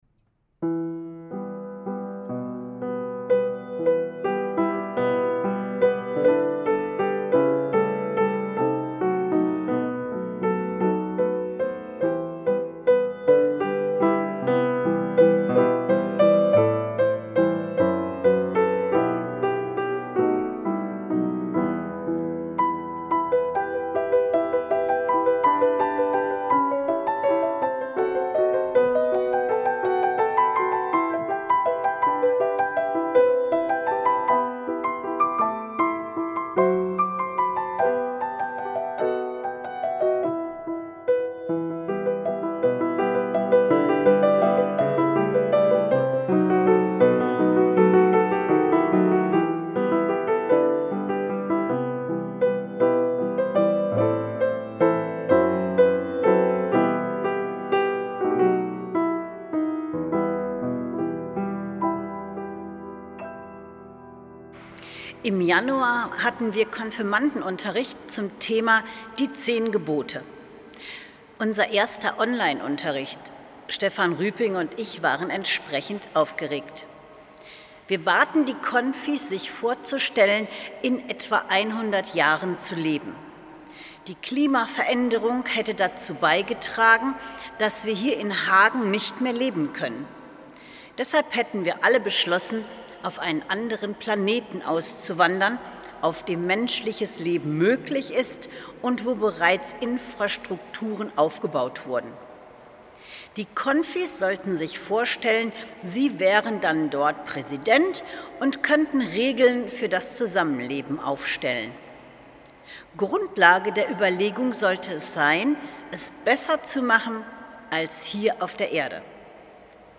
Wie eine Umarmung [Andacht